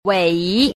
10. 偽 – wěi – ngụy
wei.mp3